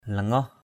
/la-ŋɔh/ Aiek → tangaoh t_z<H [Cam M] [A,169] _____ Synonyms: tangul tz~L